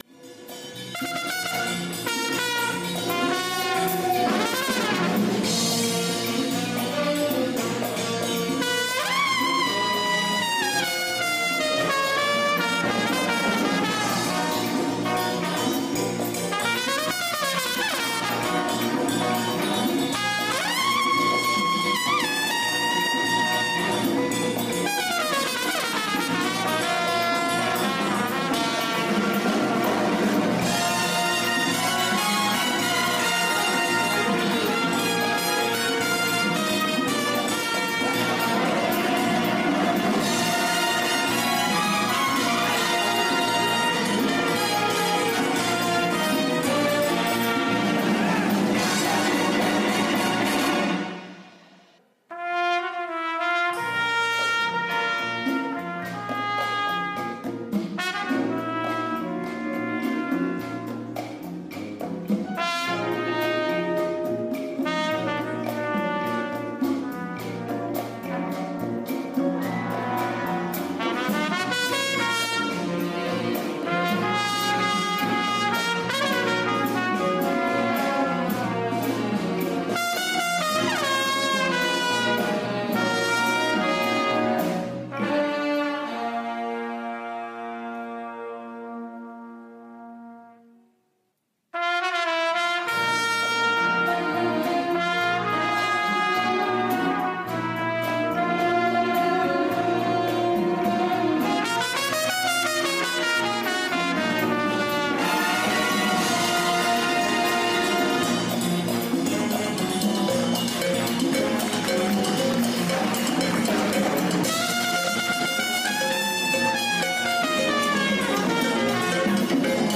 Category: big band
Style: Latin jazz mambo
Solos: trumpet 1
Instrumentation: big band (4-4-5, rhyhtm)
Featured Instrument: trumpet soloist